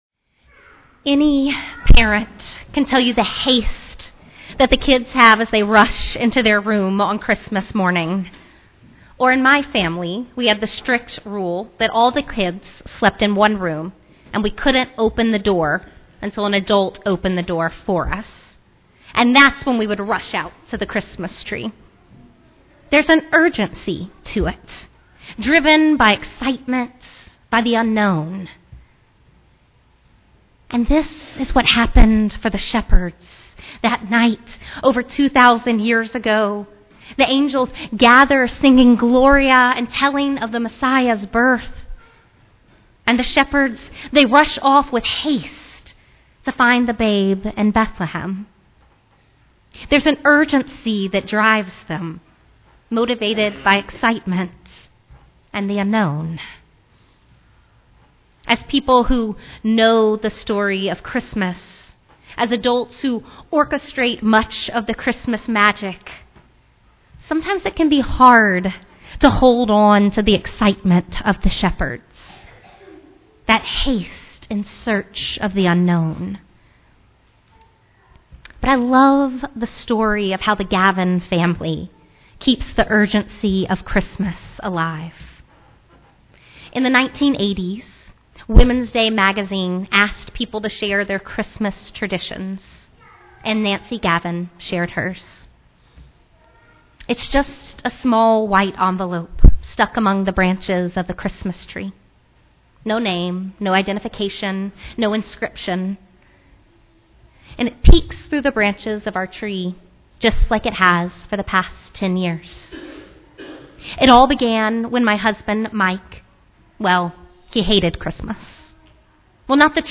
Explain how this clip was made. Christmas Eve